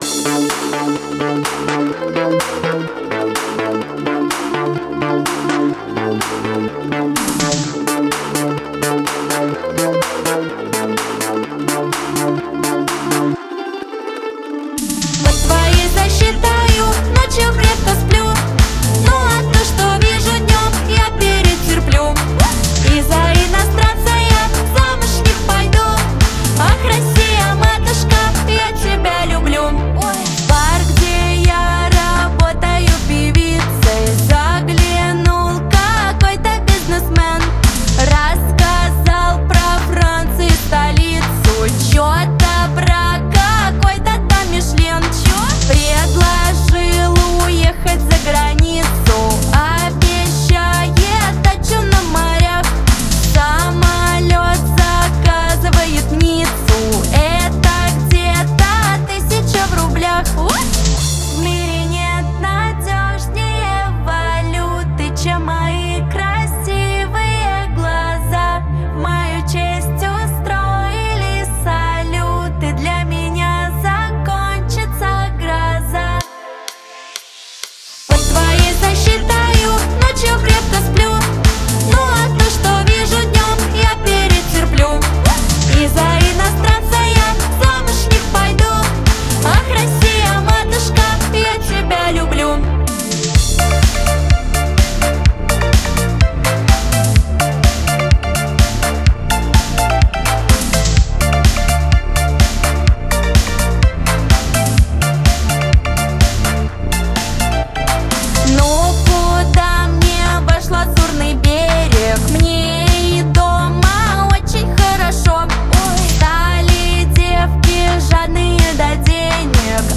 это мощная композиция в жанре альтернативного рока